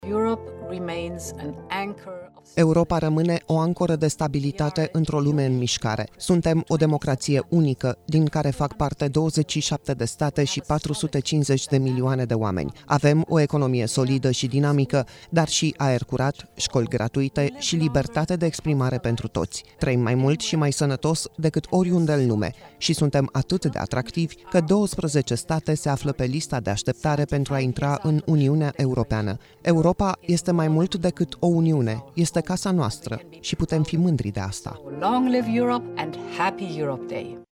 Mesajul Ursulei von der Leyen de Ziua Europei: „Europa rămâne o ancoră de stabilitate într-o lume în mișcare” | AUDIO